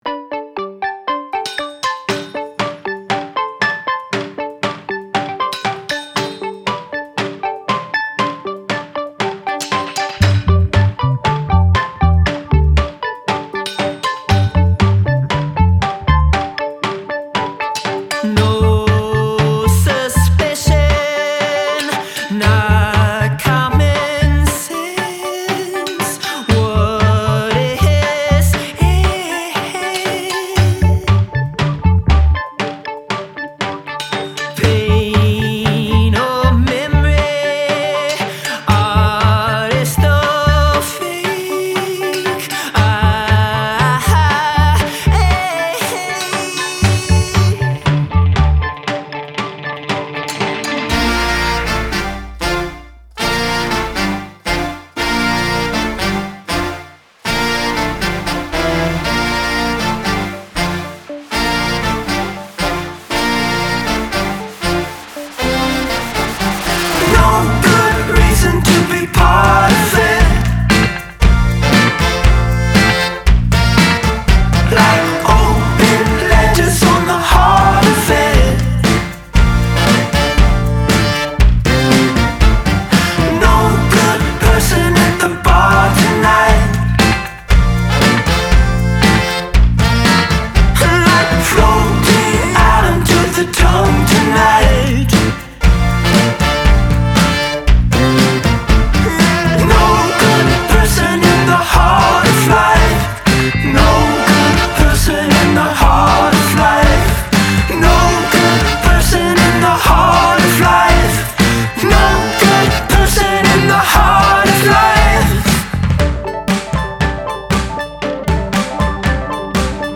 Жанр: Indie.